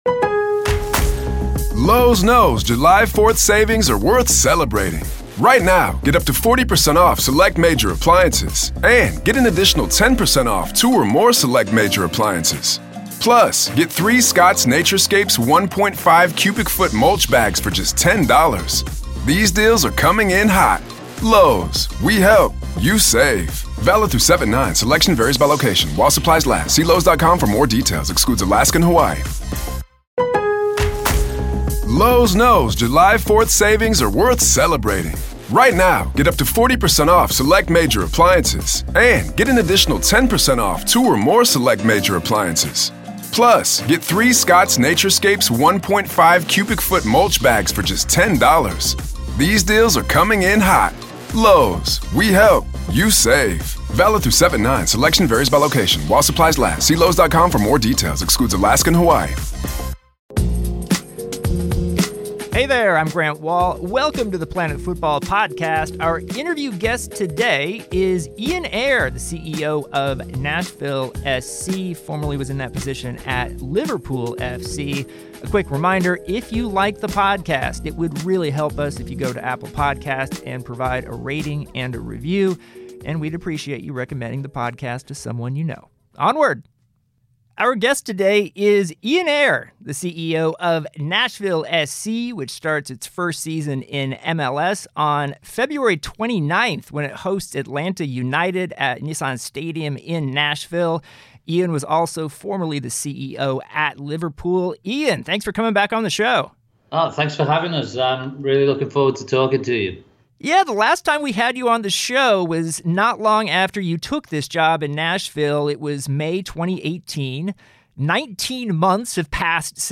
An Interview With Nashville SC CEO Ian Ayre